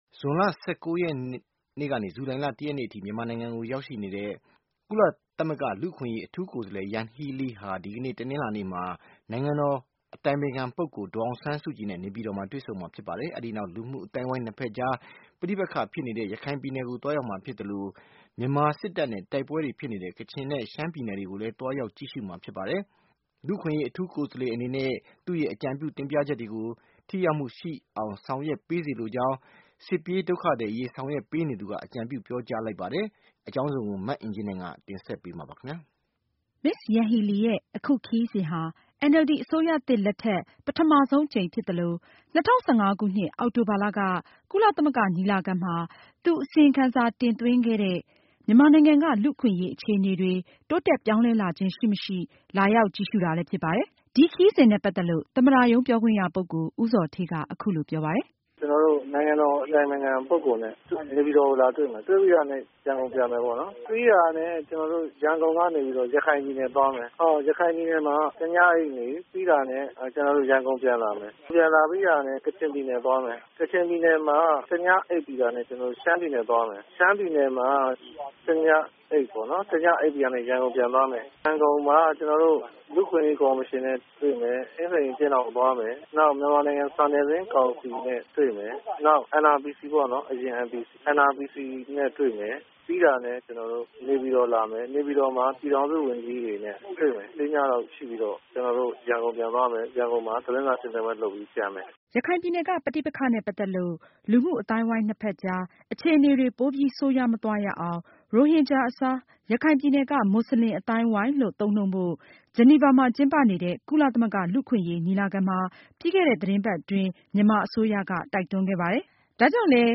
Ms. Yanghee Lee ရဲ့ အခုခရီးစဉ်ဟာ NLD အစိုးရသစ်လက်ထက် ပထမဆုံးအကြိမ်ဖြစ်သလို၊ ၂၀၁၅ အောက်တိုဘာလက ကုလသမဂ္ဂညီလခံမှာ သူ အစီရင်ခံ တင်သွင်းခဲ့တဲ့ မြန်မာနိုင်ငံက လူ့အခွင့်အရေးအခြေအနေတွေ တိုးတက်ပြောင်းလဲလာခြင်းရှိမရှိ လာရောက်ကြည့်ရှုတာ လည်းဖြစ်ပါတယ်။ ဒီကနေ့ နေပြည်တော်ကို သွားရောက်ပြီး ဒေါ်အောင်ဆန်းစုကြည်နဲ့တွေ့ဆုံမယ့် အစီအစဉ်အပါအဝင် Ms. Yanghee Lee ရဲ့အခြားခရီးစဉ်နဲ့ပတ်သက်လို့ သမ္မတရုံးပြောခွင့်ရပုဂ္ဂိုလ် ဦးဇော်ဌေးက အခုလိုပြောပါတယ်။